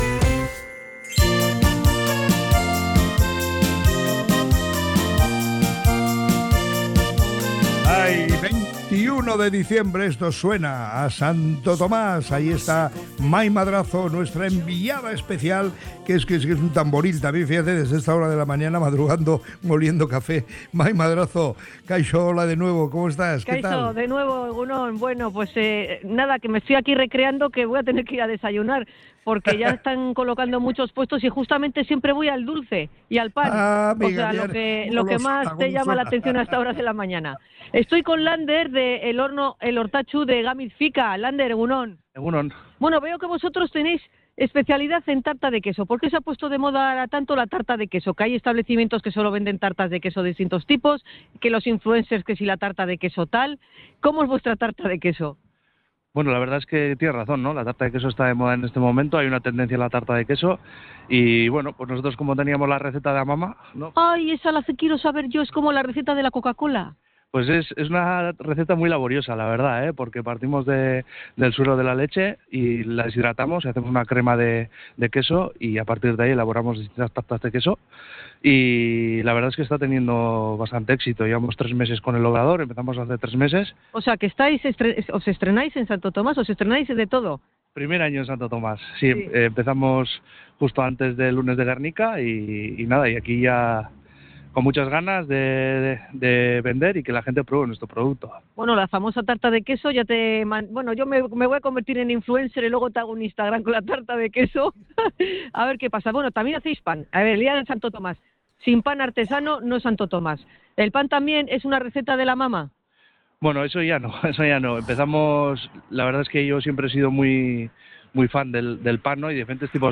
ha recorrido los puestos de Santo Tomás en el Arenal bilbaíno